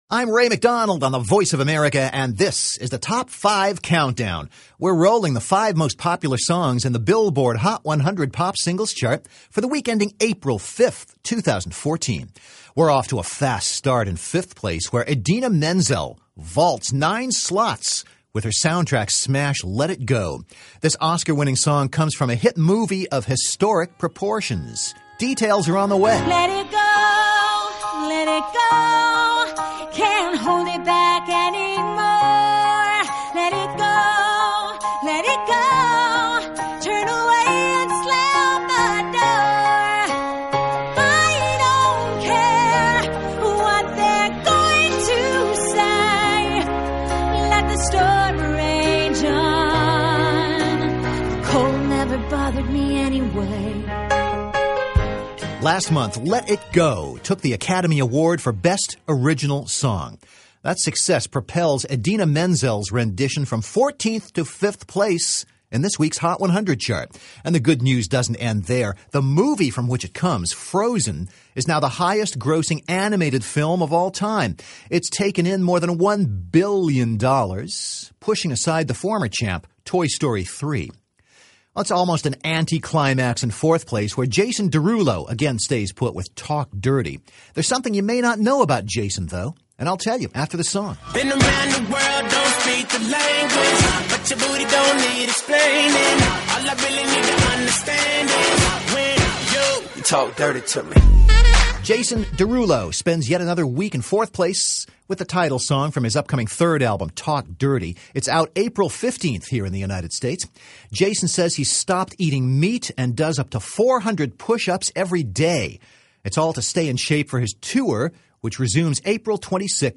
Top 5 Countdown for Week Ending April 5